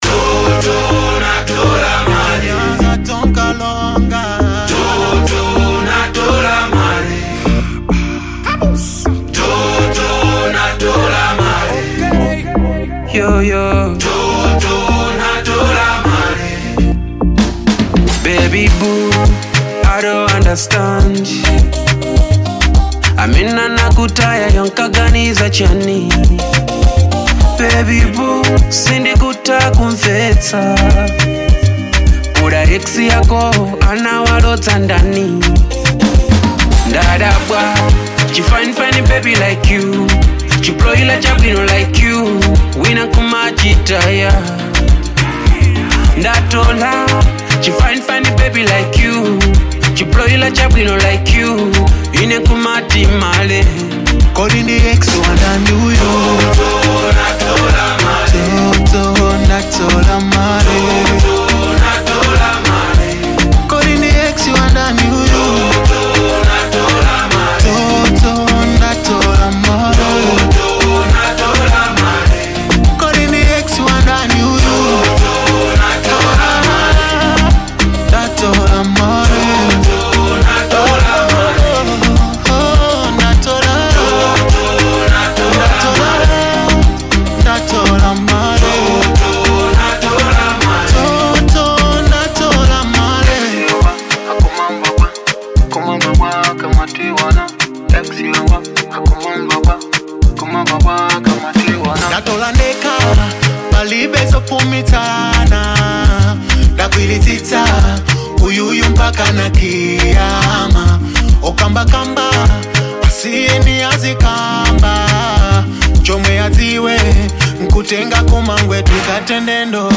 Genre : Afro Beat
Afro beat